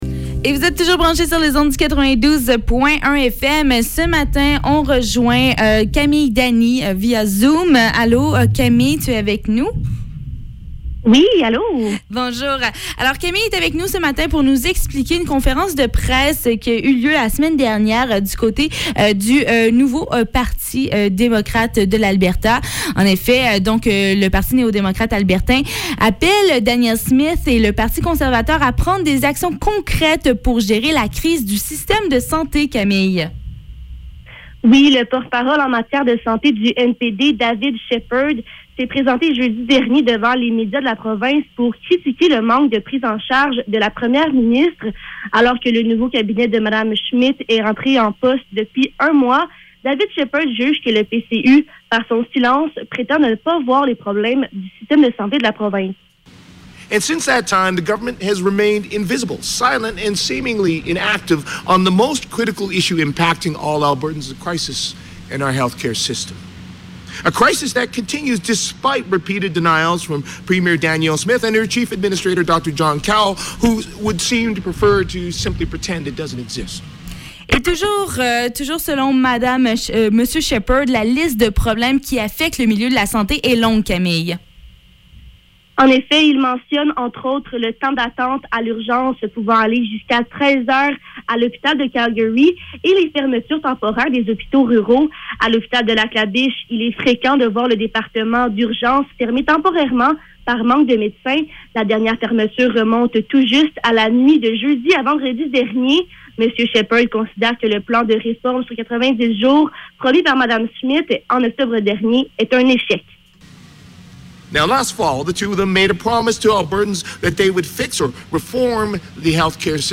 NPD-critics-David-Shepherd-Reportage.mp3